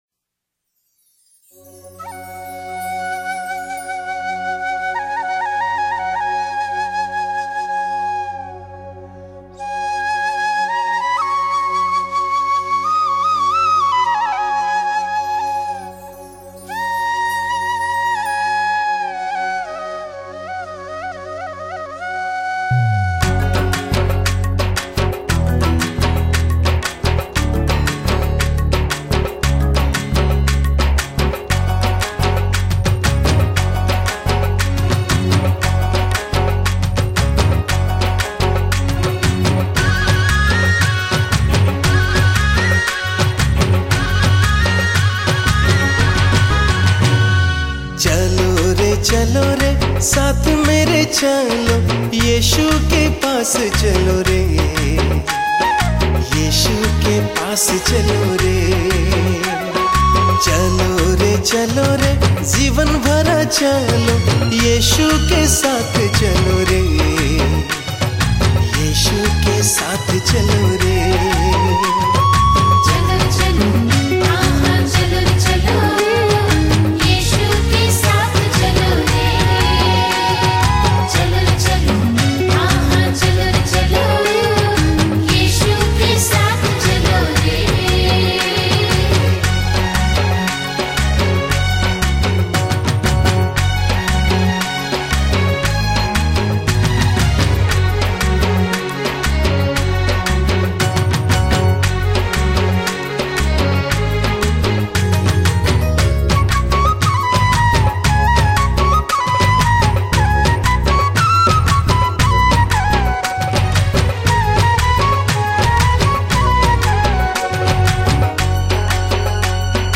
Hindi Gospel Songs | Global Chakma Christian Fellowship